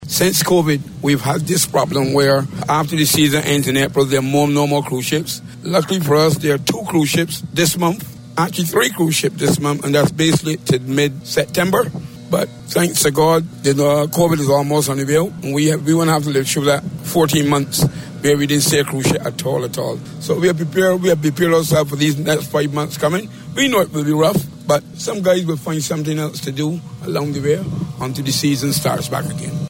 A member of the Bridgetown Port Taxi Co-op notes however, that there will be a few cruise ships calls between now and the start of the next cruise season in October.